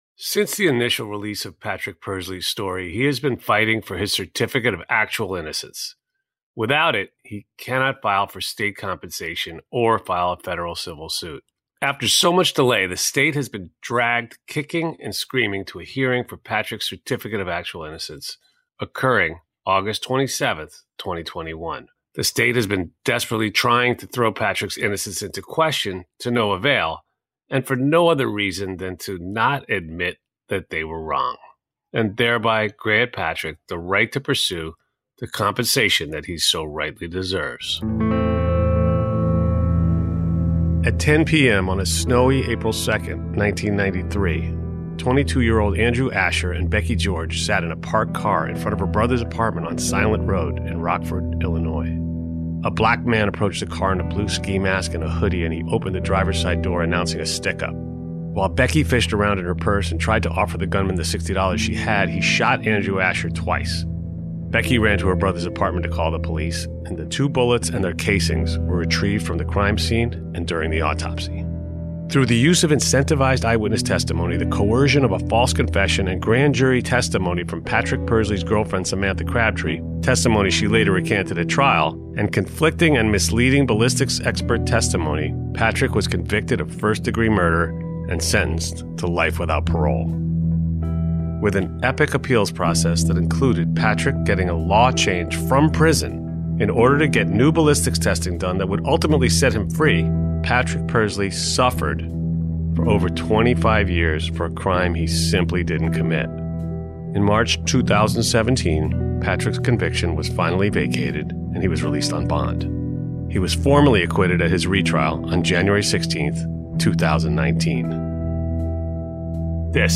In this live audience recording at ComplexCon Chicago 2019